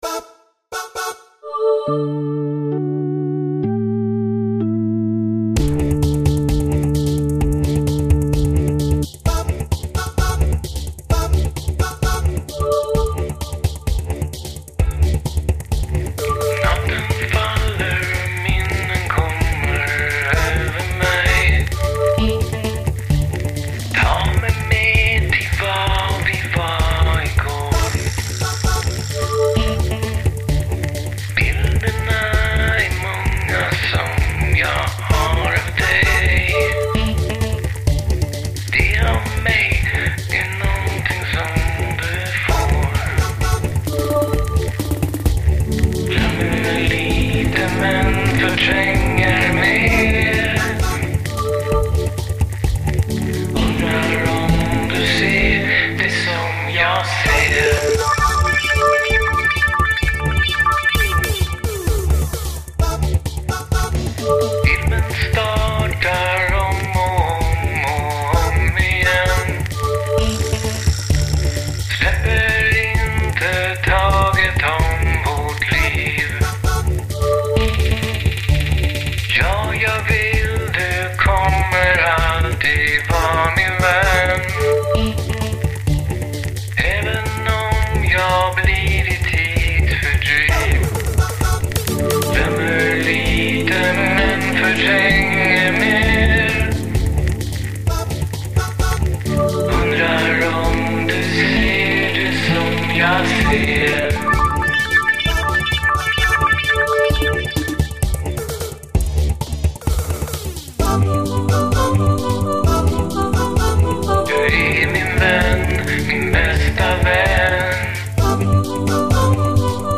som började med ett studsande basljud